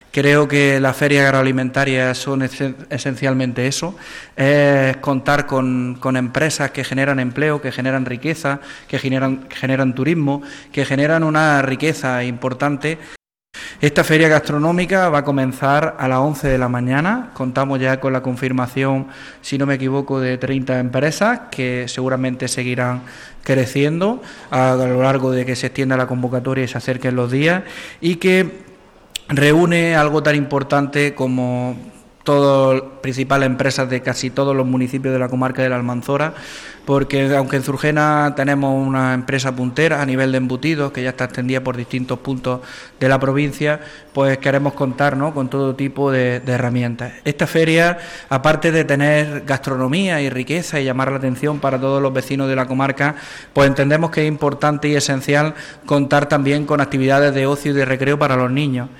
La Diputación Provincial de Almería ha acogido este mediodía la presentación de la I Feria Gastronómica y Artesanal de Zurgena.
El acto ha contado con la participación del diputado provincial de Promoción Agroalimentaria, Carlos Sánchez, así como del delegado territorial, Francisco Alonso, y el alcalde del municipio, Domingo Trabalón; que han estado acompañados por la edil Isabel Sola.